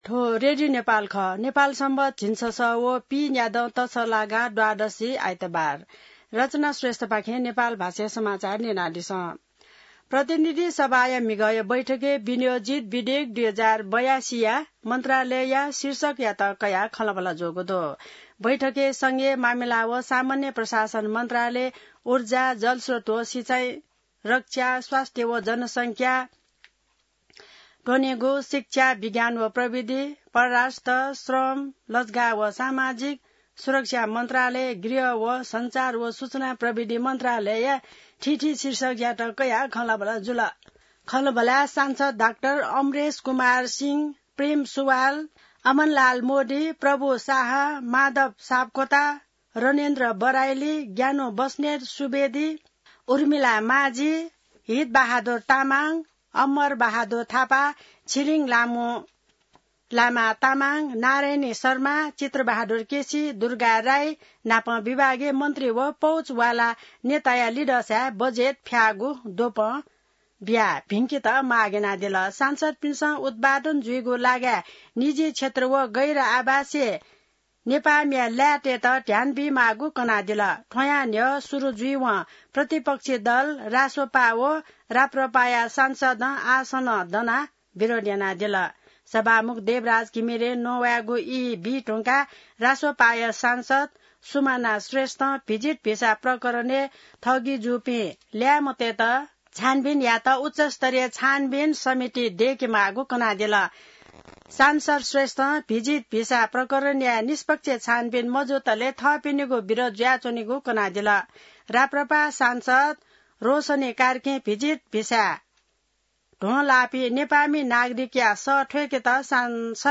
नेपाल भाषामा समाचार : ८ असार , २०८२